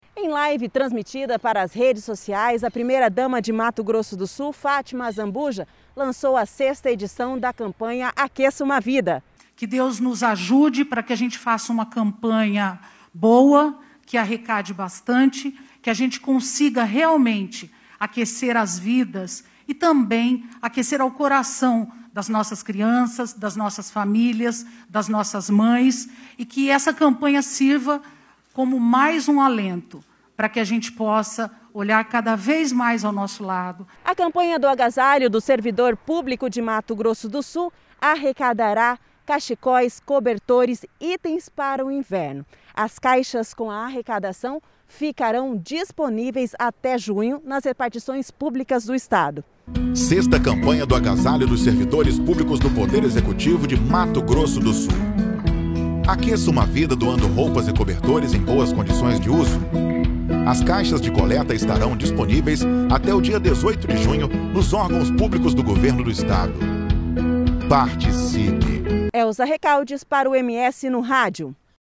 BOLETIM-CAMPANHA-AGASALHO.mp3